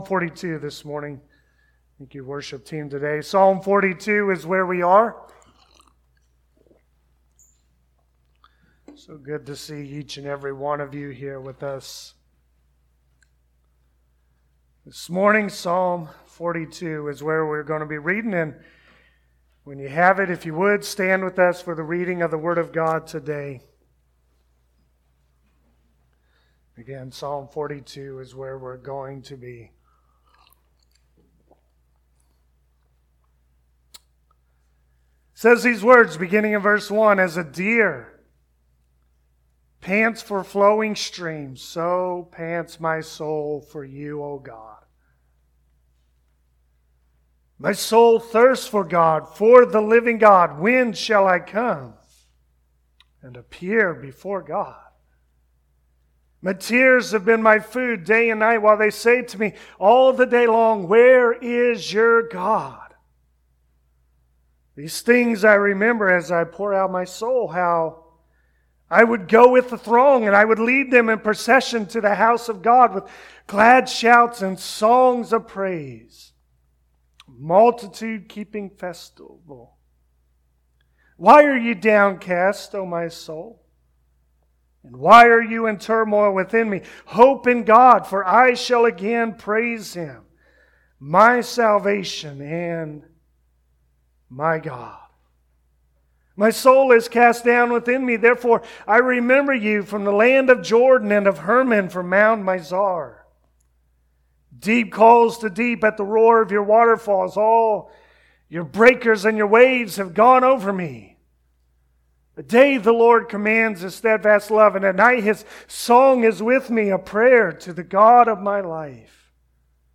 Passage: Psalm 42 Service Type: Sunday Morning Even in the midst of our worst trials, we can turn to a God who always gives hope.